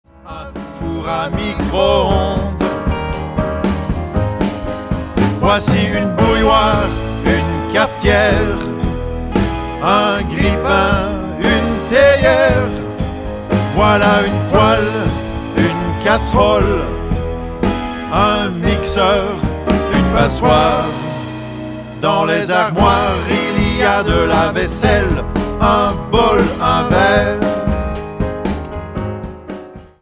Genre : World